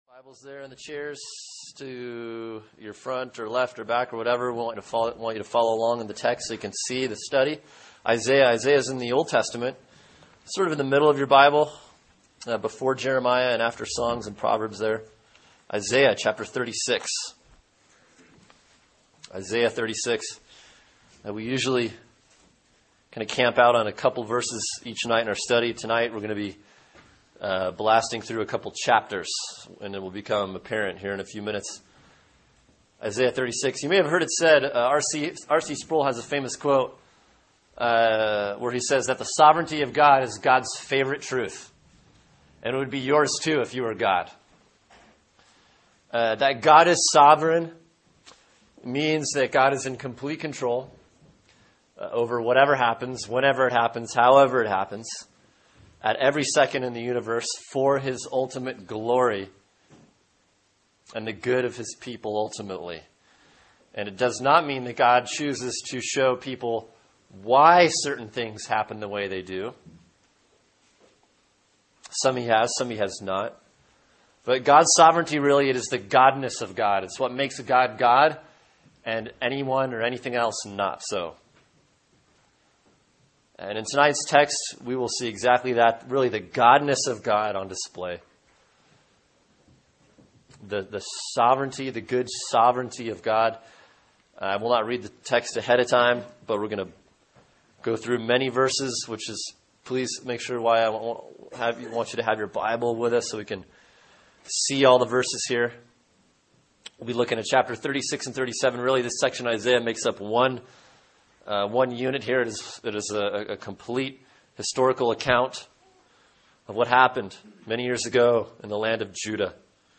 Sermon: Isaiah 36 & 37 “God Is The Hero” | Cornerstone Church - Jackson Hole